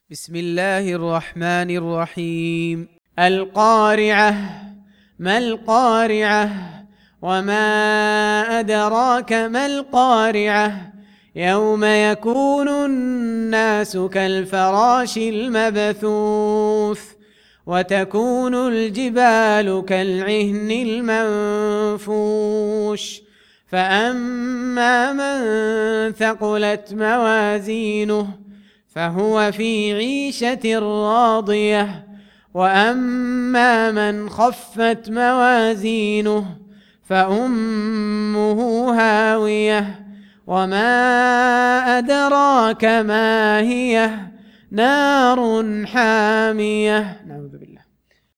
Surah Sequence تتابع السورة Download Surah حمّل السورة Reciting Murattalah Audio for 101. Surah Al-Q�ri'ah سورة القارعة N.B *Surah Includes Al-Basmalah Reciters Sequents تتابع التلاوات Reciters Repeats تكرار التلاوات